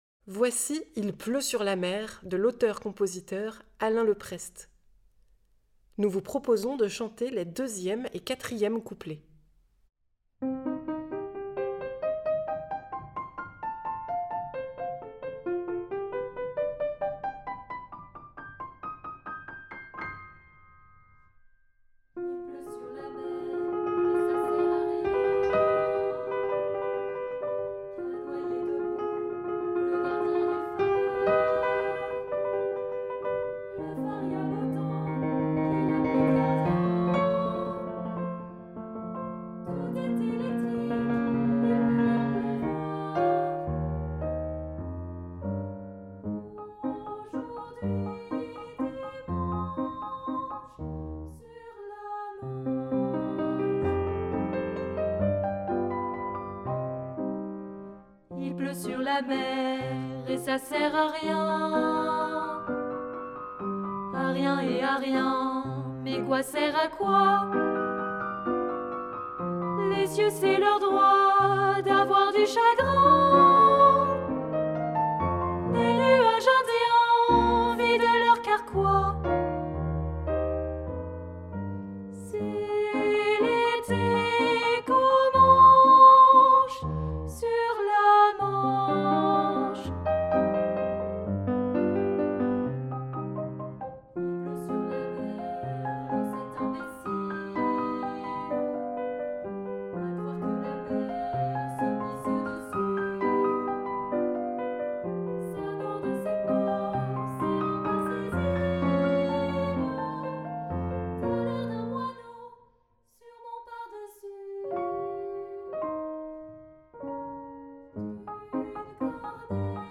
Genre :  Chanson
Version tutti choeur participatif